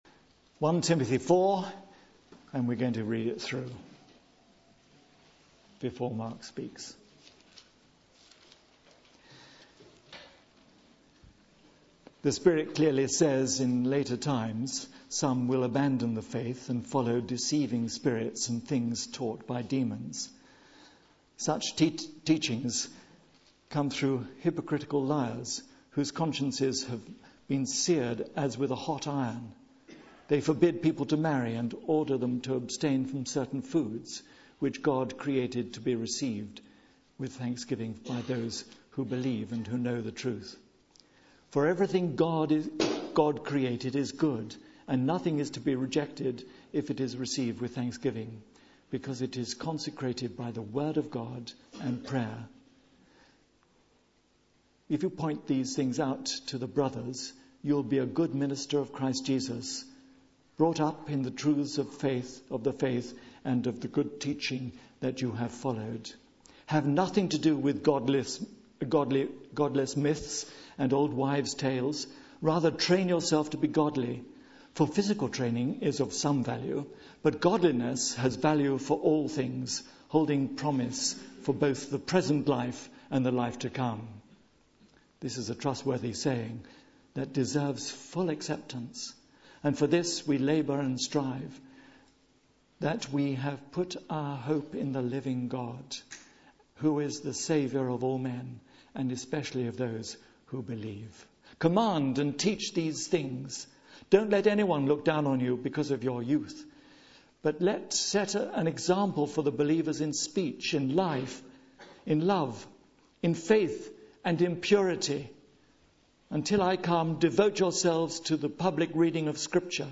Passage: 1 Timothy 4.1-16 Series: The Pastoral Epistles Theme: Sermon